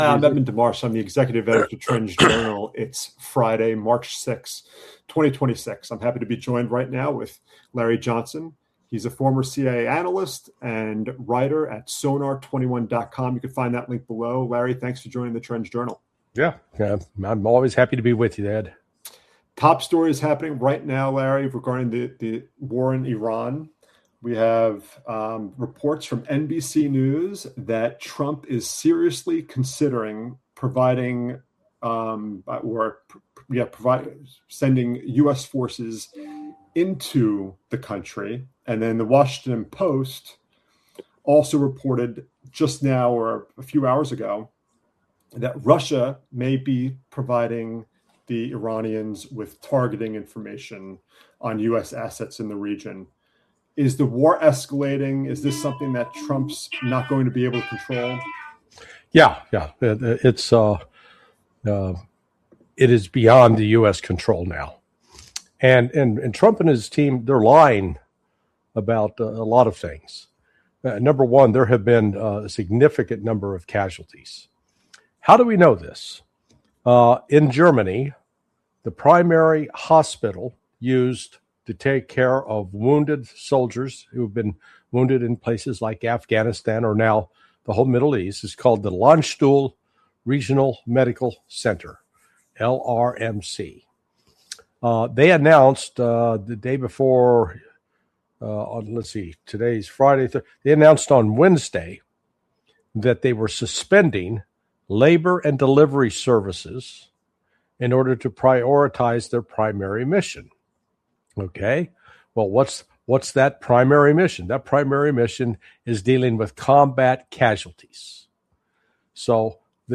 In a tense interview